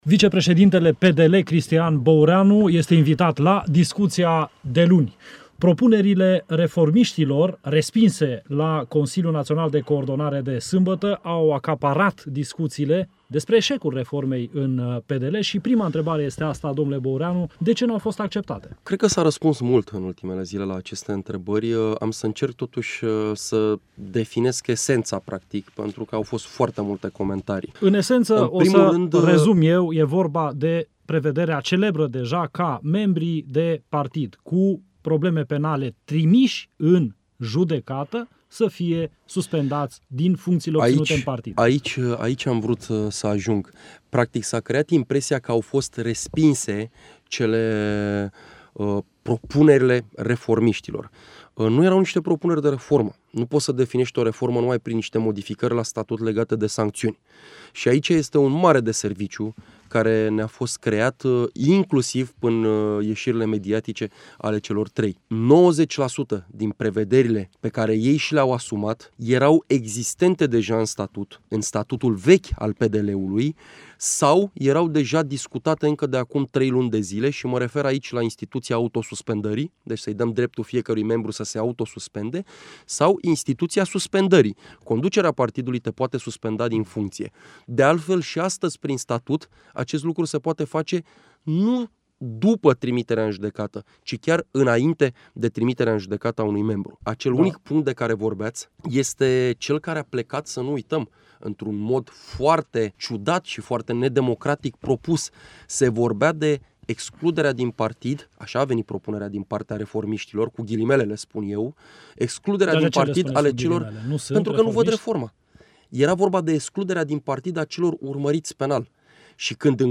Asculta integral interviul cu vicepresedintele PDL, Cristian Boureanu